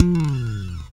Index of /90_sSampleCDs/Roland - Rhythm Section/BS _Jazz Bass/BS _E.Bass FX